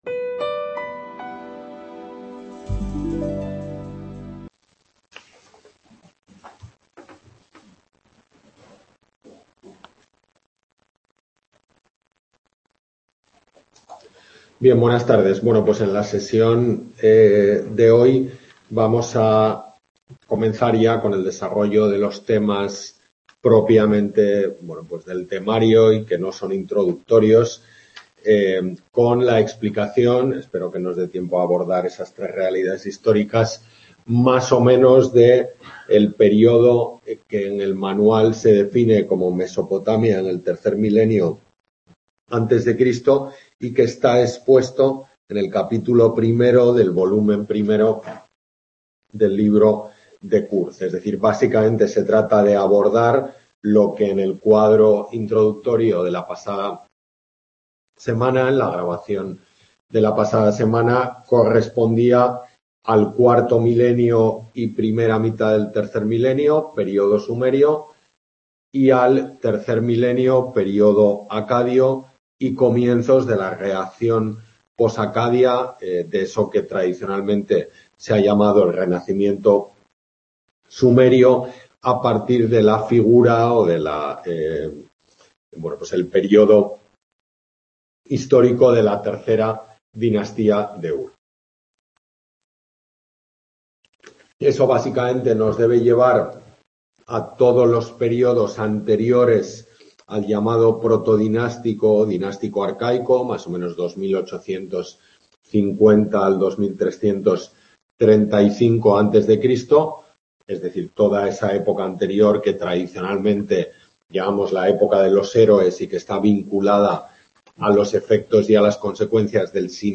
Tutoría de Historia Antigua I en la UNED de Tudela